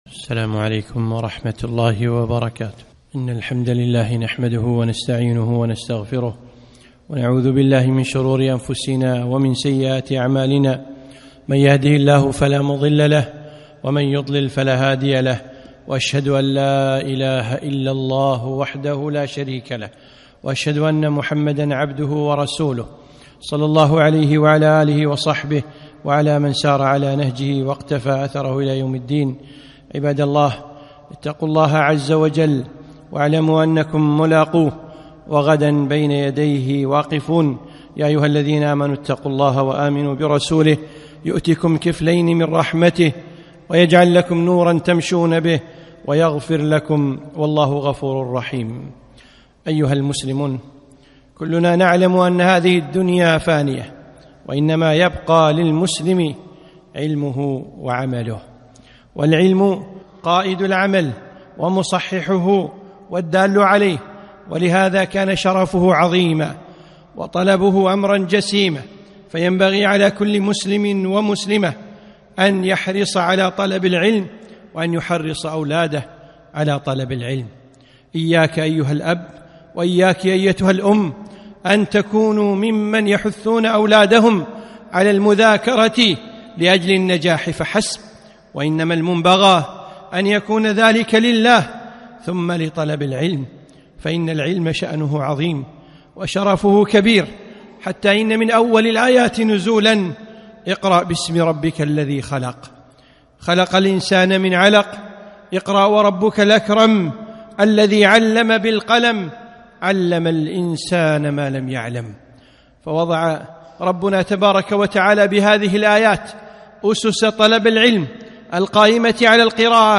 خطبة - طلب العلم